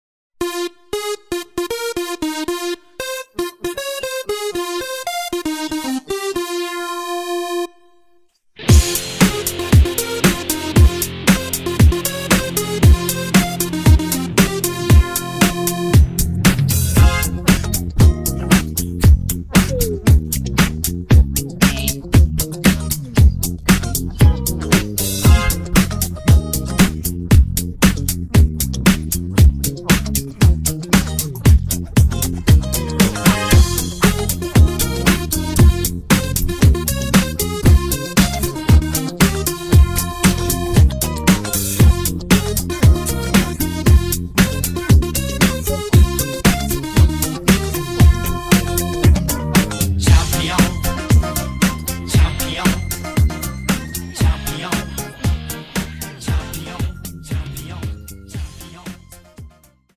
음정 원키 3:09
장르 가요 구분 Voice MR